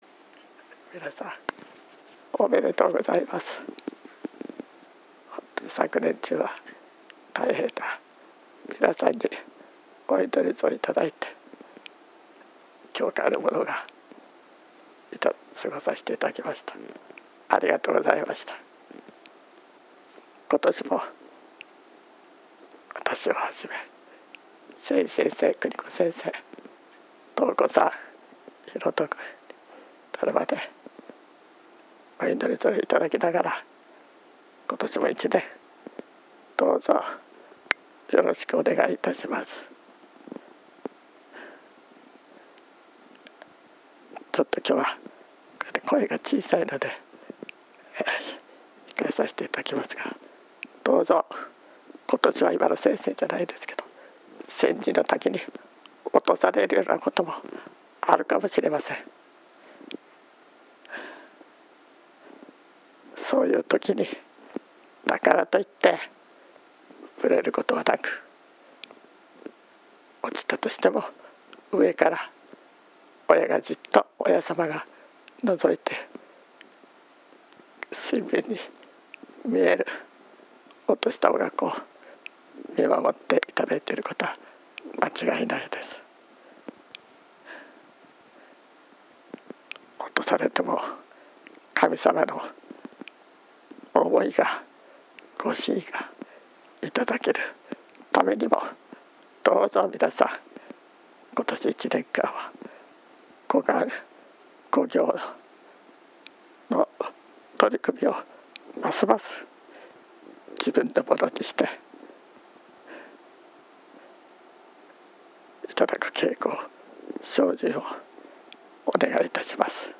22.01.01 元日祭教会長挨拶